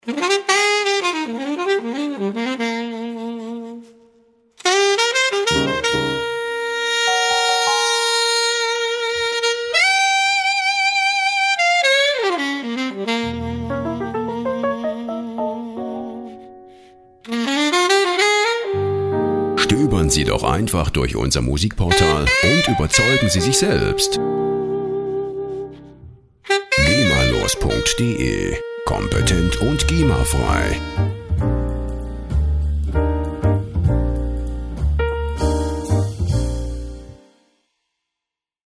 Instrument: Tenorsaxophon
Tempo: 204 bpm